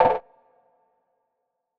PERC - DUMB.wav